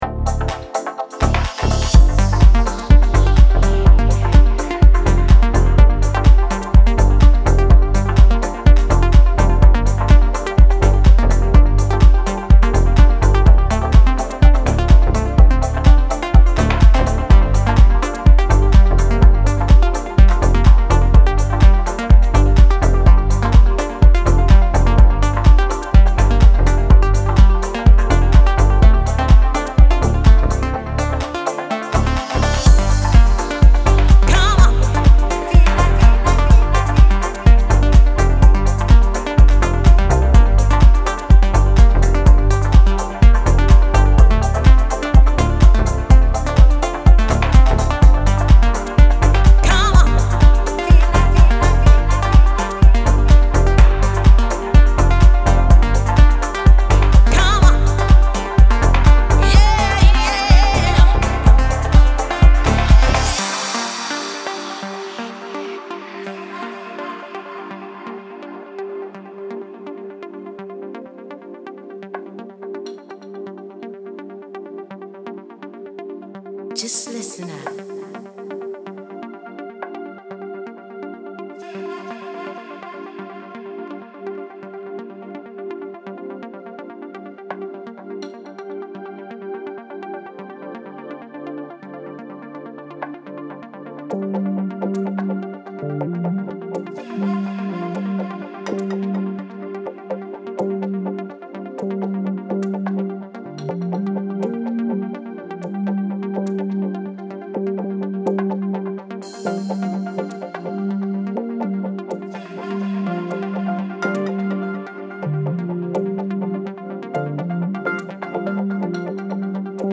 Deep House, Chillout - нужна критика/совет на свежее ухо
Стиль сам не знаю... толи дипхаус, толи чилаут.
даю середину с входом в яму и выходом.
Вложения deepchill_demo.mp3 deepchill_demo.mp3 4,7 MB · Просмотры: 191